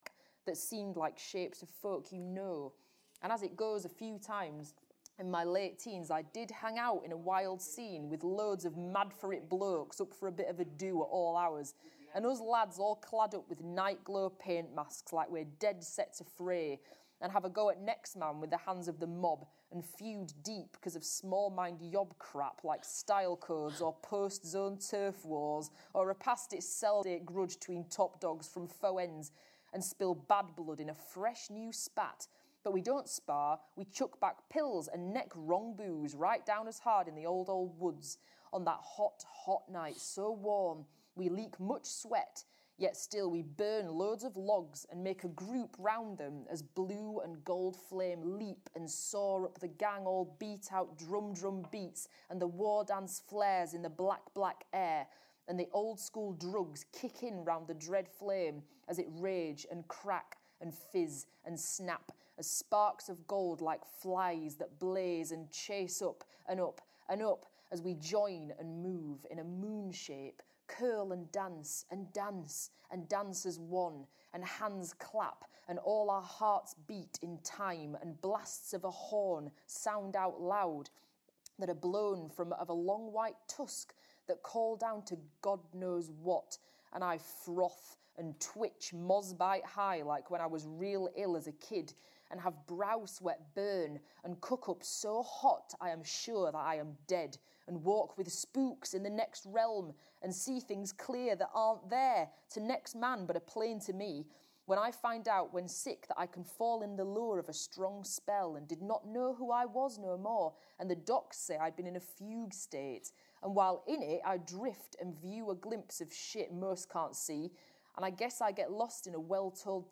the-sentence-fragment-from-british-library-reading.mp3